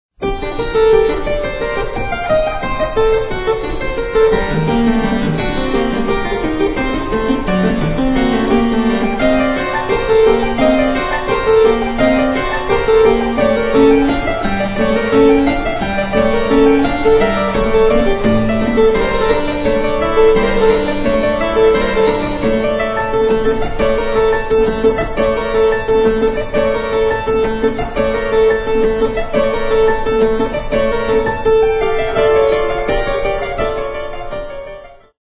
harpsichordist
Sonata for keyboard in G minor, K. 12 (L. 489) - 4:43